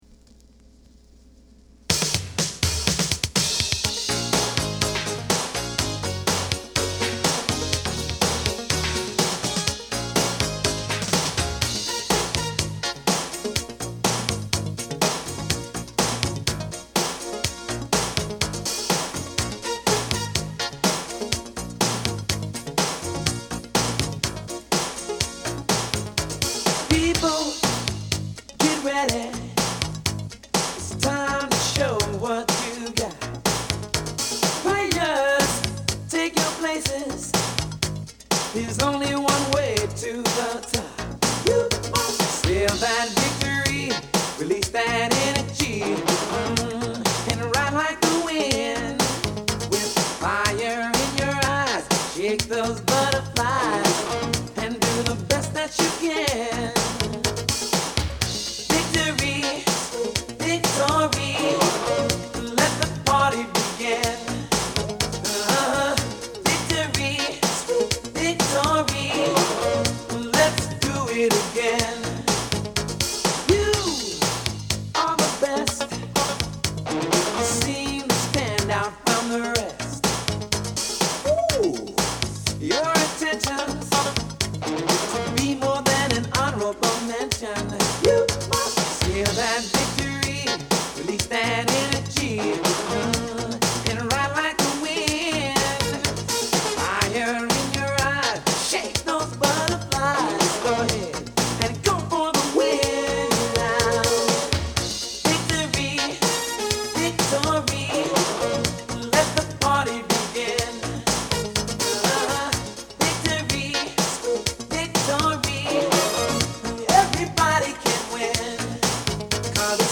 Genre: R&B / Dance / Soul
軽快な出だしから、バンドの勢いがそのまま伝わってくる。